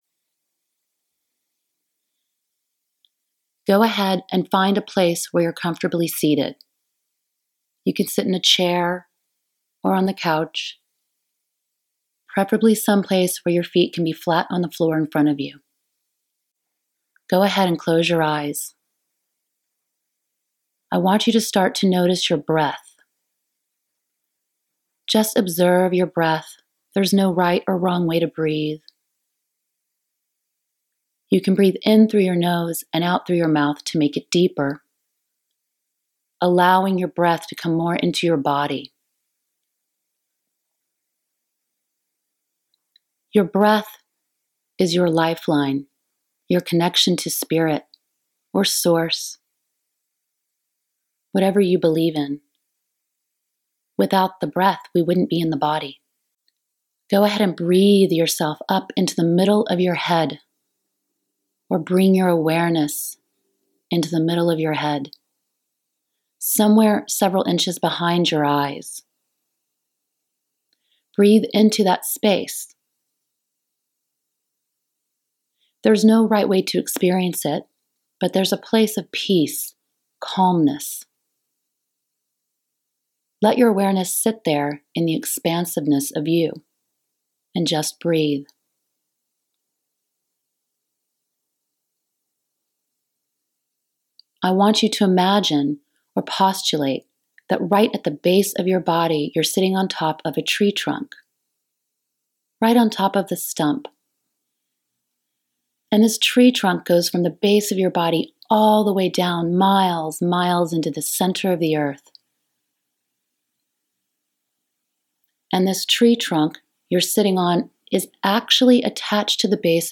6 Minute Meditation